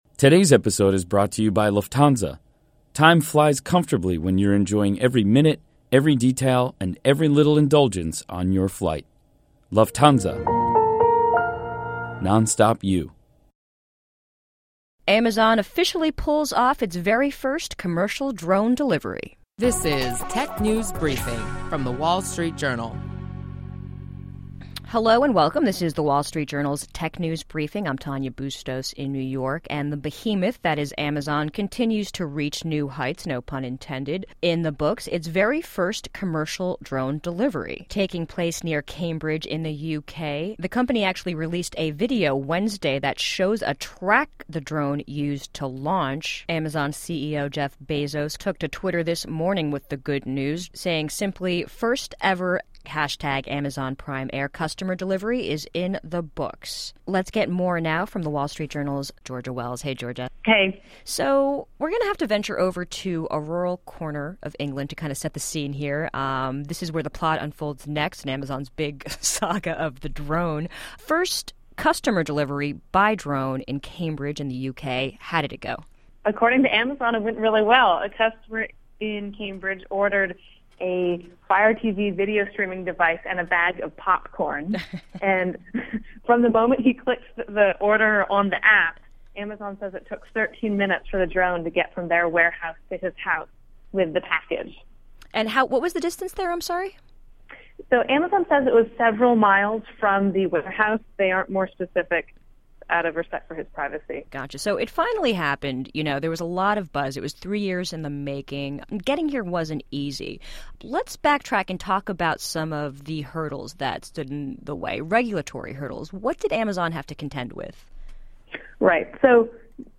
Stay informed on the latest trends with daily insights on what’s hot and happening in the world of technology. Listen to our reporters discuss notable company news, new tech gadgets, personal technology updates, app features, start-up highlights and more.